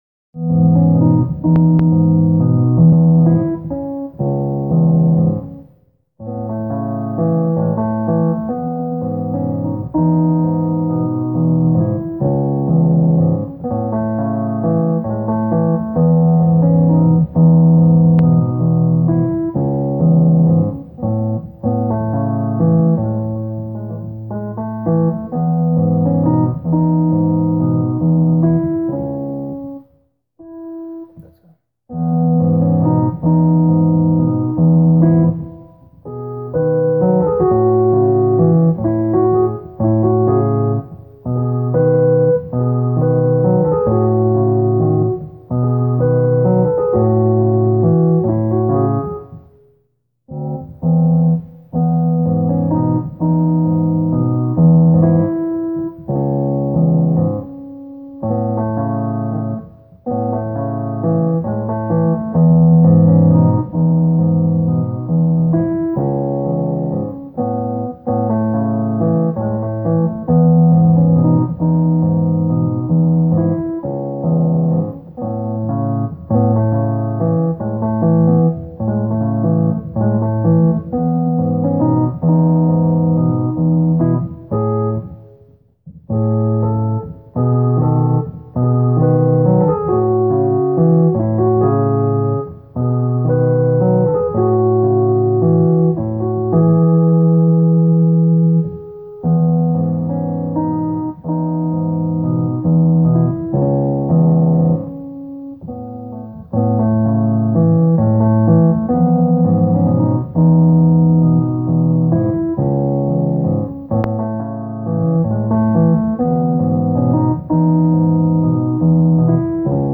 Teresa and the Man of Obsidian (piano) 1.m4a